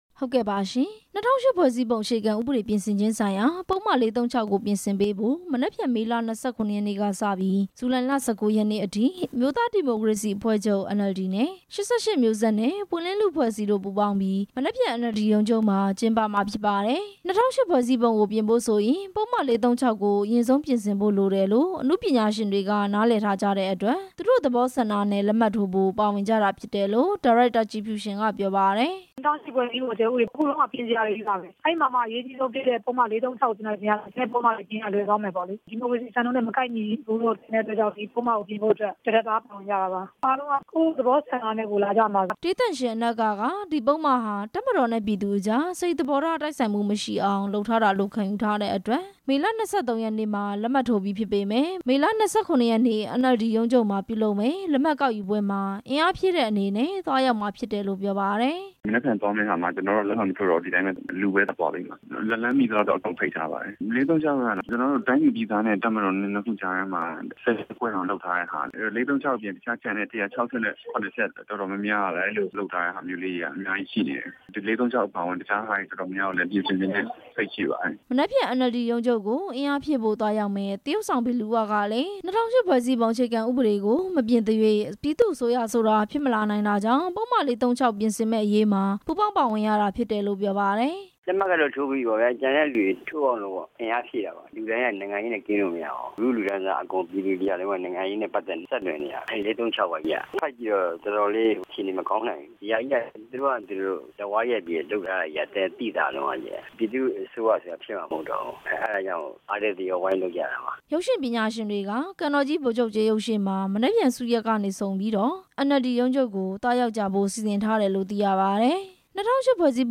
မနက်ဖြန်မနက် လက်မှတ်ရေးထိုးပွဲ အစီအစဉ်နဲ့ ပတ်သက်ပြီး ဒါရိုက်တာ ကြည်ဖြူသျှင်က အခုလိုပြောပါတယ်။